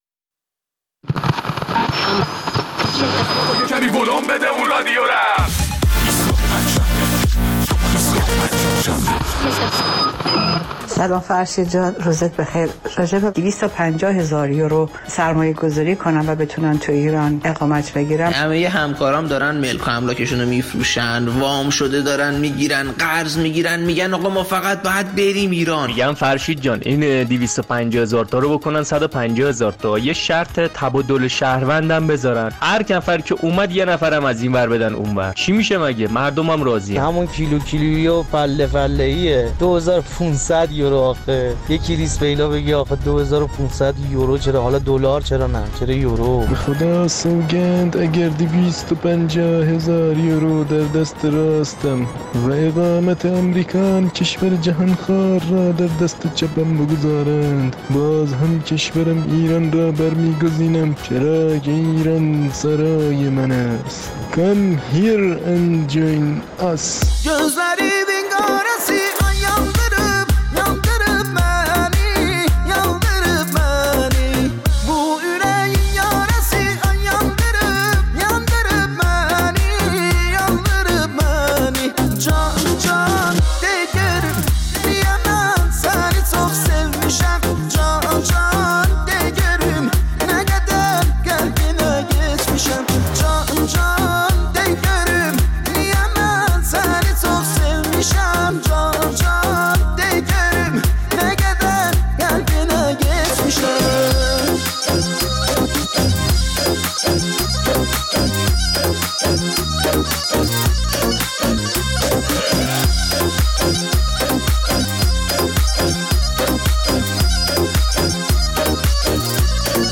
در این برنامه ادامه نظرات مخاطبانمان را درباره ارائه اقامت ۵ ساله به افراد غیرایرانی در صورت سرمایه‌گذاری در ایران می‌‌شنویم.